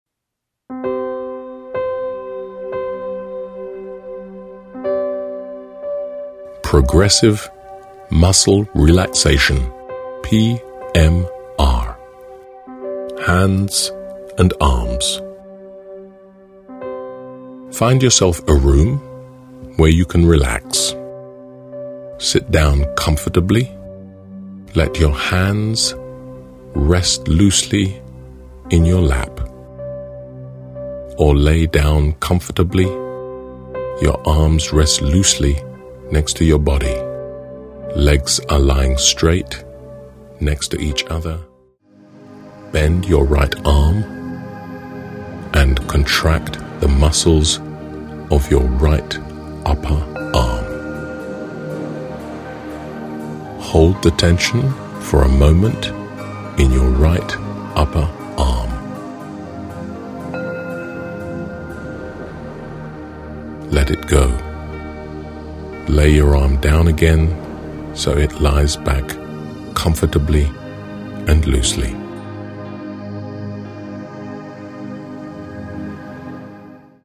This Audiobook is a guide for your self-studies and learning.